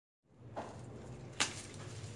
Tag: 环境 atmophere 记录